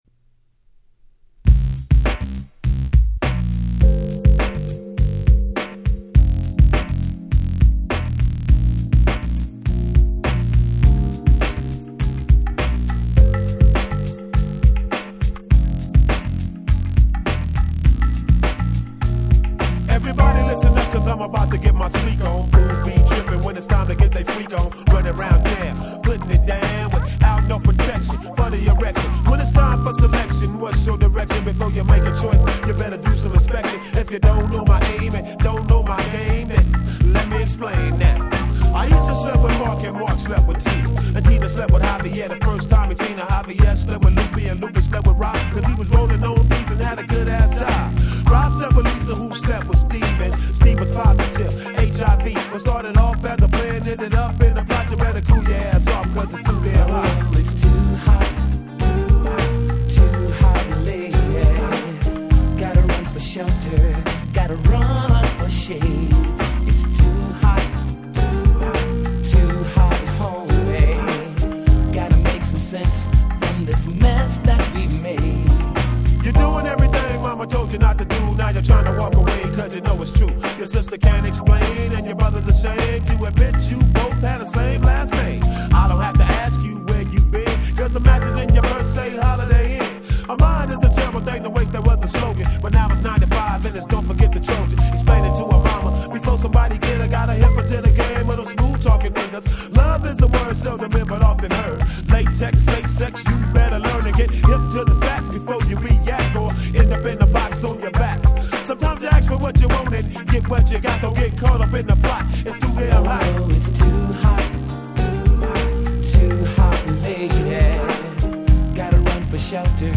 95' Super Hit West Coast Hip Hop !!